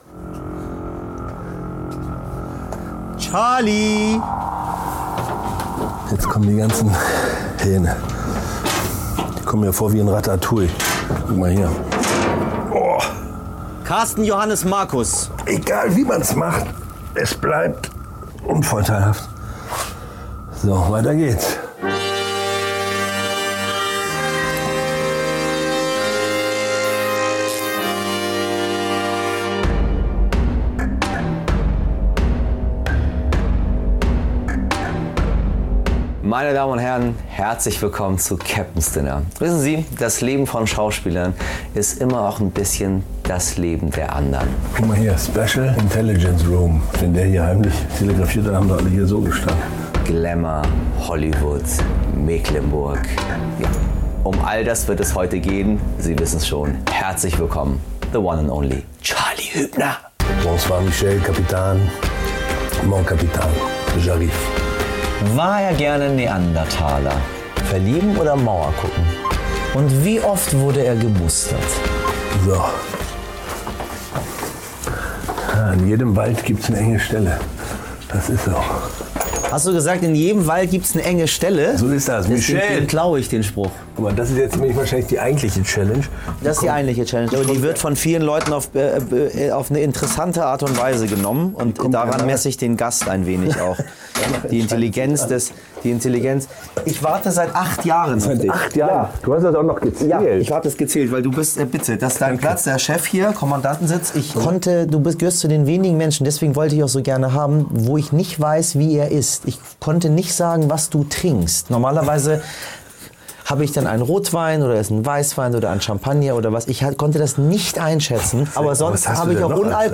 Bei Michel Abdollahi im U-Boot ist Regisseur und Schauspieler Charly Hübner zu Gast.